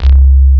RIPMOOG C2-L.wav